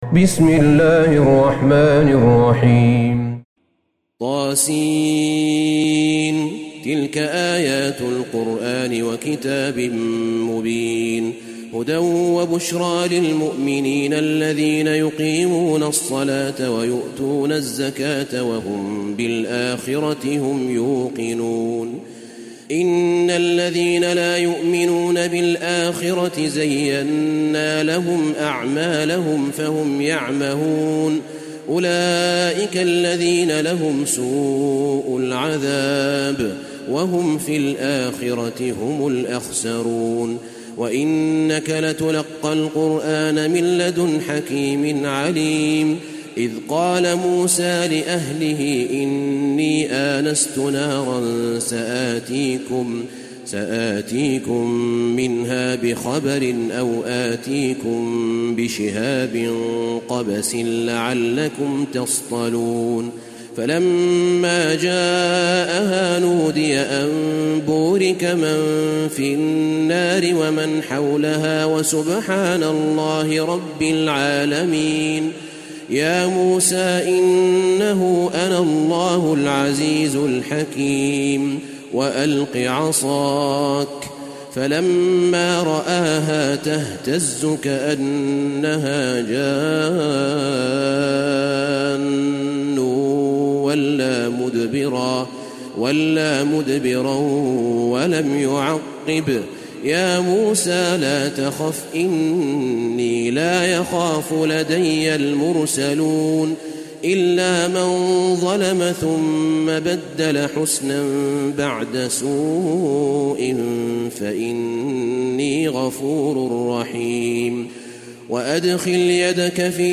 سورة النمل Surat AnNaml > مصحف الشيخ أحمد بن طالب بن حميد من الحرم النبوي > المصحف - تلاوات الحرمين